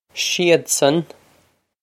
Pronunciation for how to say
she-od-son
This is an approximate phonetic pronunciation of the phrase.